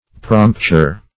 Meaning of prompture. prompture synonyms, pronunciation, spelling and more from Free Dictionary.